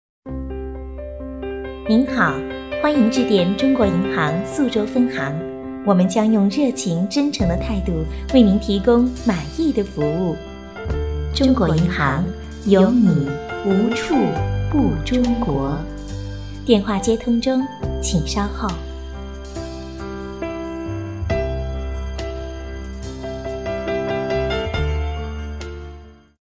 女声配音
彩铃女国70